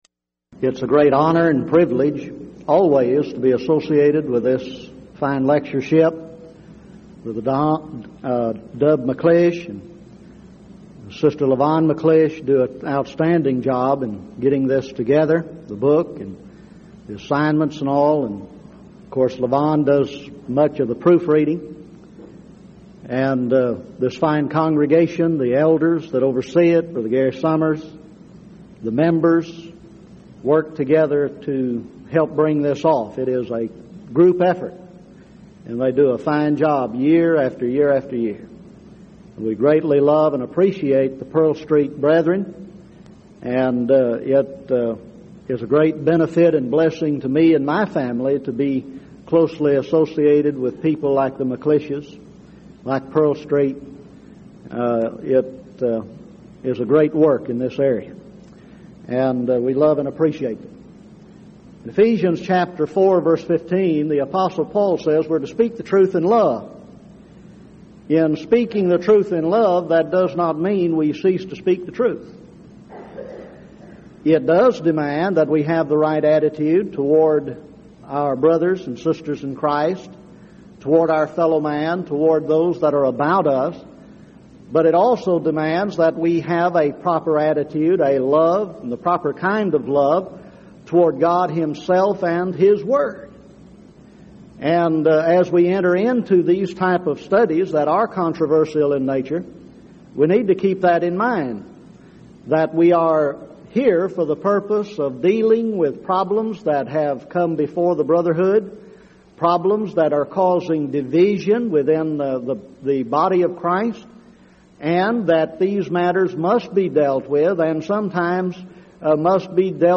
Event: 1999 Denton Lectures
lecture